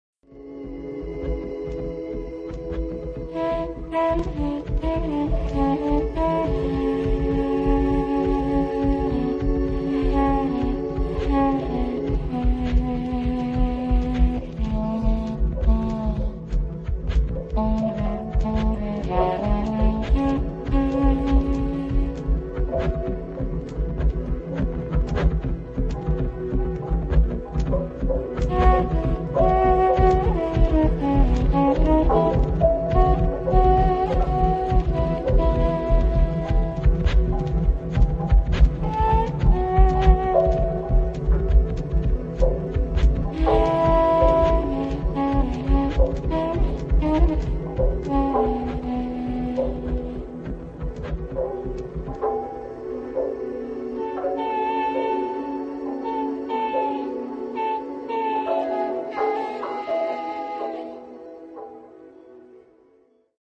trumpet, voice, keyboards and electronics
keyboards
guitars and bow